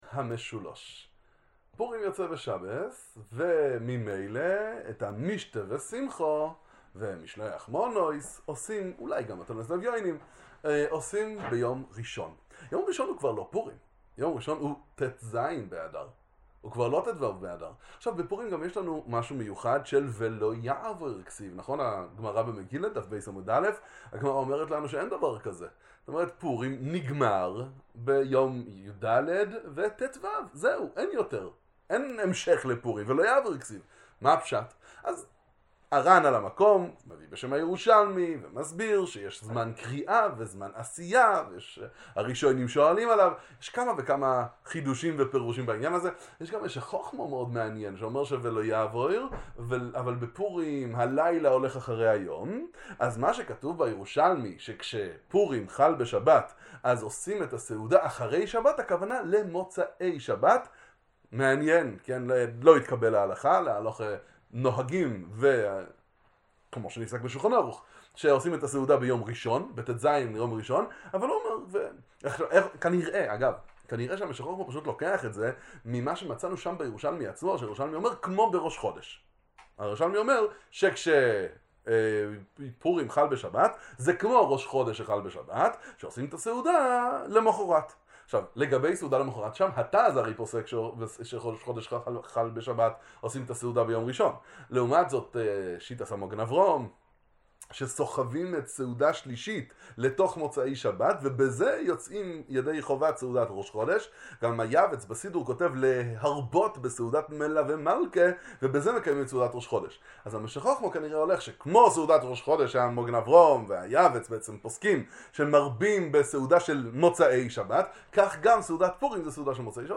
דבר תורה קצר לפורים המשולש